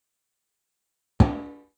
bang.wav